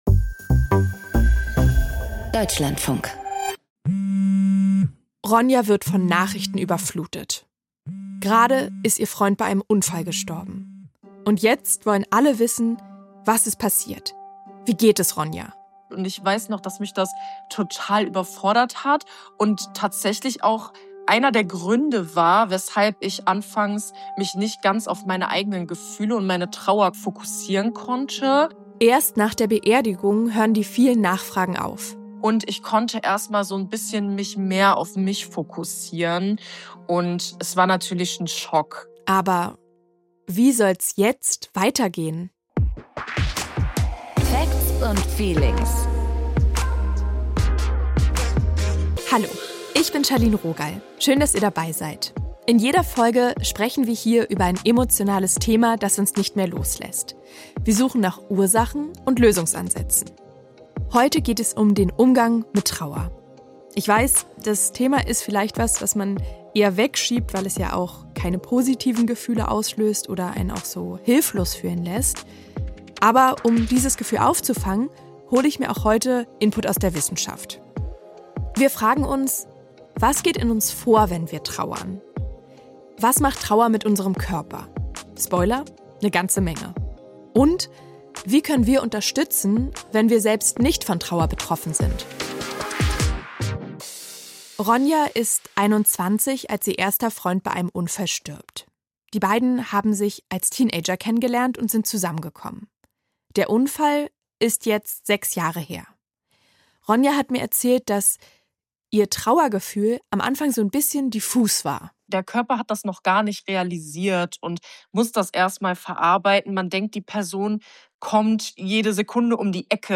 Sie erzählt, wie sie mit dem Verlust umgegangen ist. Trauer ist sehr individuell: Sich nicht alleine gelassen zu fühlen, kann helfen, sagt eine Trauermentorin.